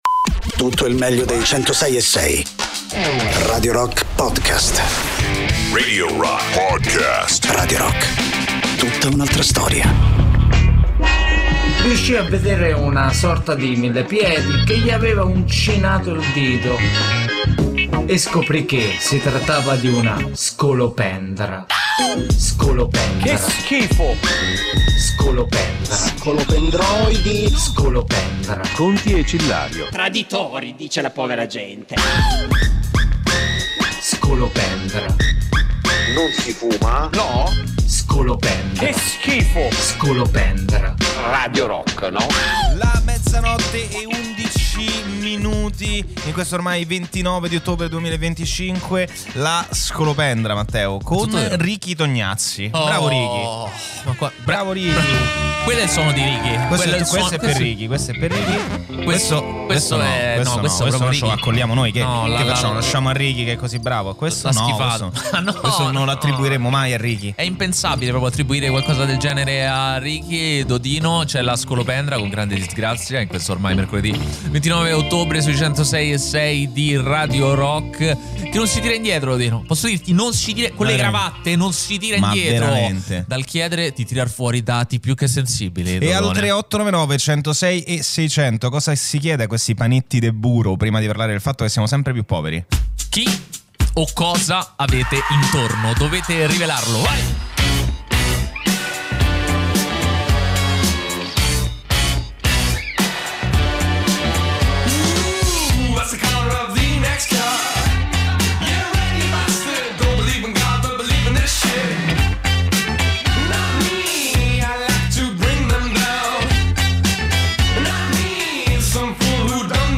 in diretta